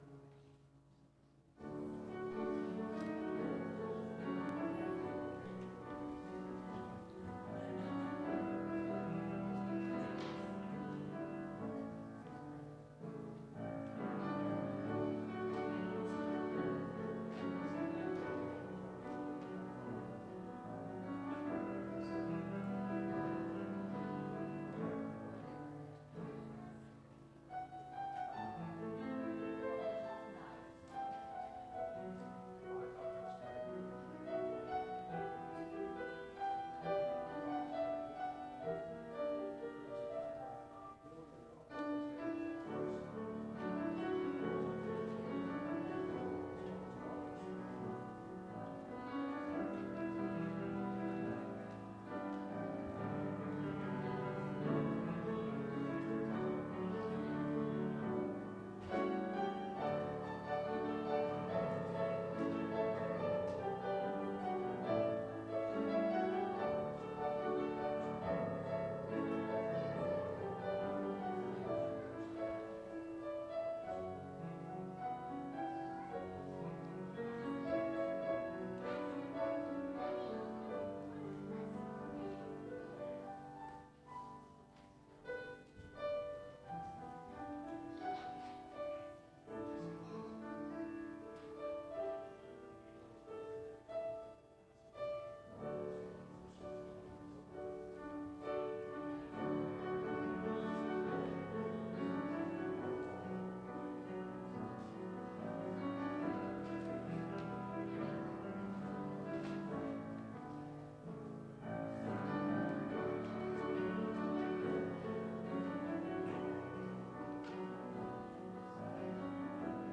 Calvin Christian Reformed Church Sermons
ORDER OF WORSHIP